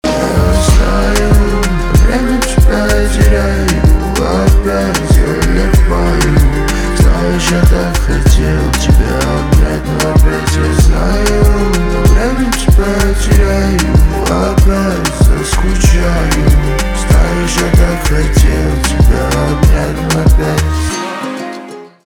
поп
битовые , гитара , грустные , печальные
чувственные